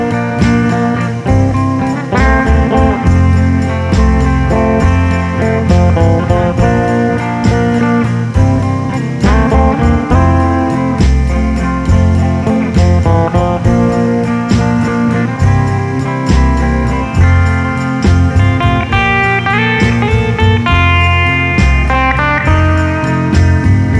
no Backing Vocals Rock 'n' Roll 3:53 Buy £1.50